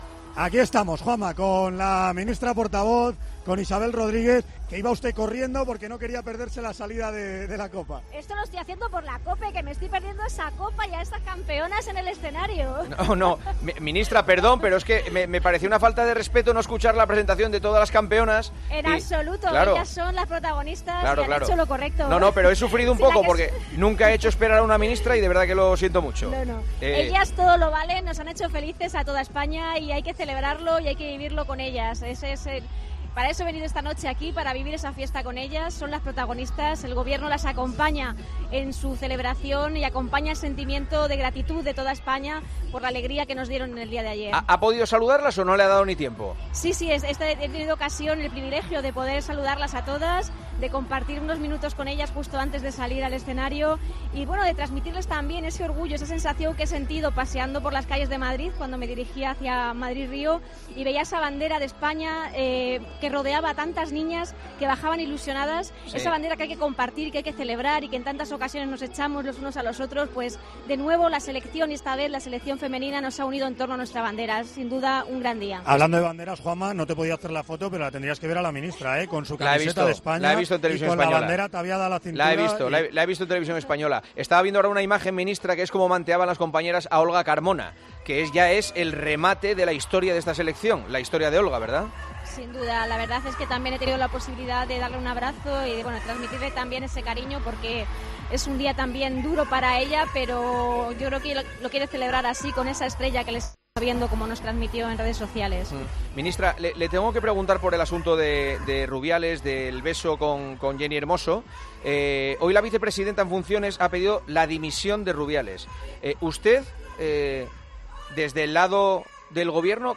Juanma Castaño entrevista a la ministra portavoz de Gobierno para hablar de la polémica de Luis Rubiales y su beso a Jenni Hermoso.